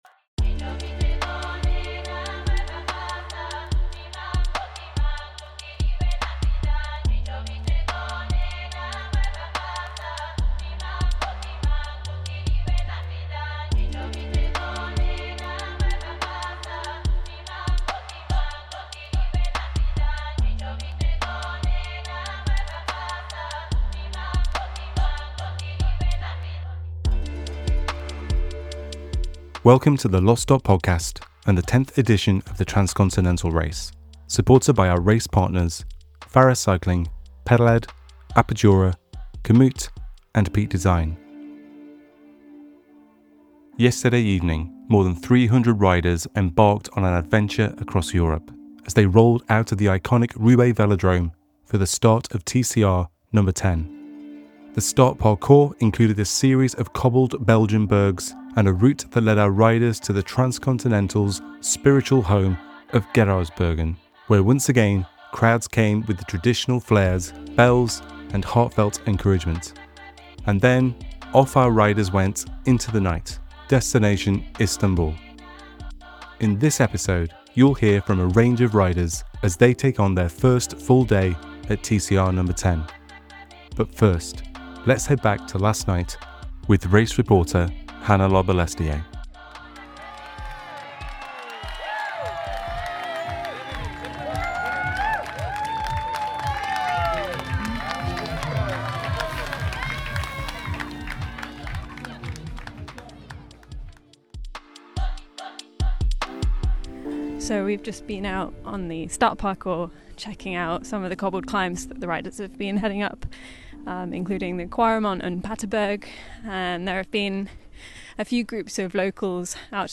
Spurred on by the intensity of the night before, the first rest decisions of the Race are being made and riders are already finding out where their limits lie. With the first major route split of the Race already visible, hear from our Race Reporters and riders on the road to piece together the beginning of the emerging TCRNo10 story. Follow along with the 10th edition of the Transcontinental Race; the self-supported bicycle race across Europe.